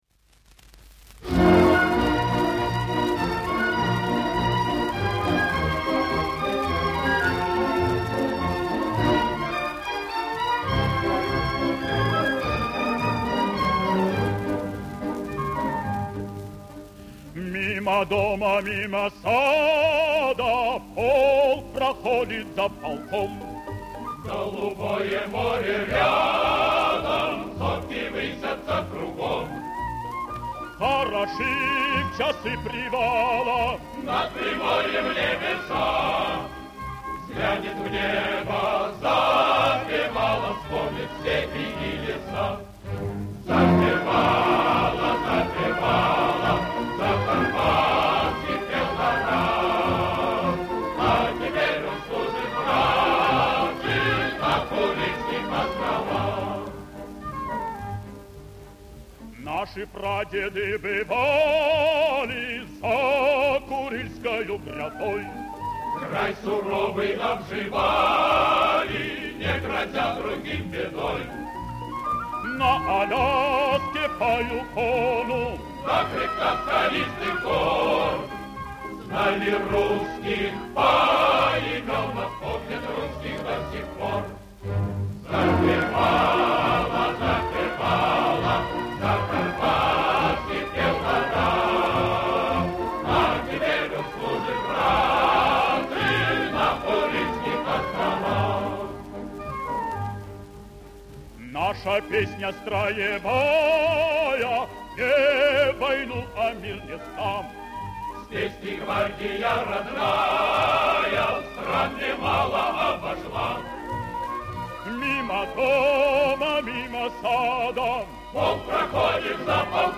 Мужской хор и оркестр